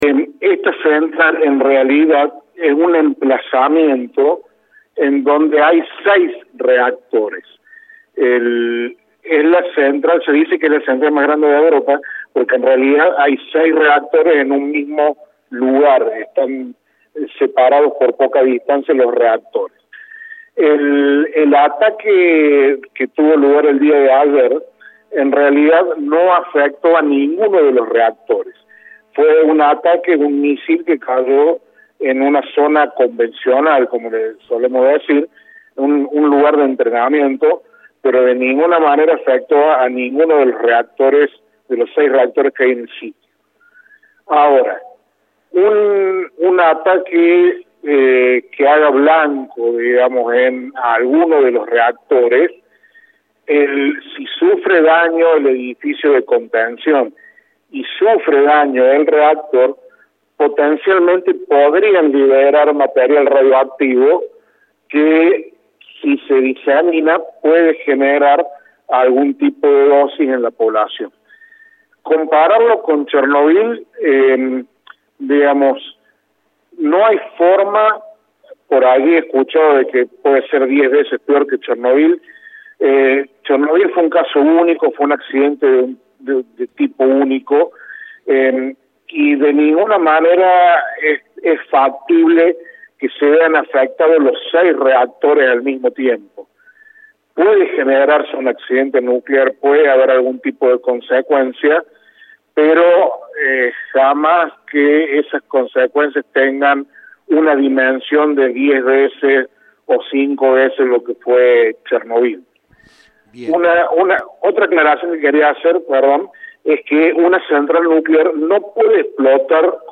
habló con Cadena 3 sobre la situación de la planta de Zaporiyia, en Ucrania.